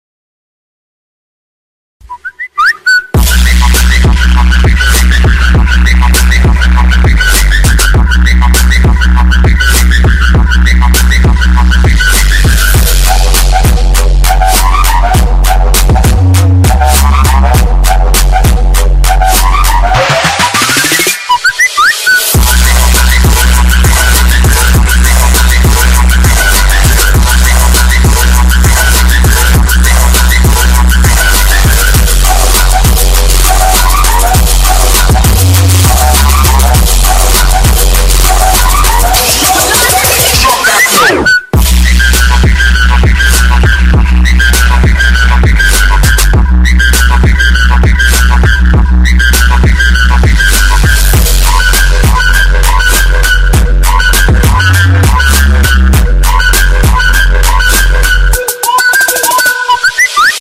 Content warning: loud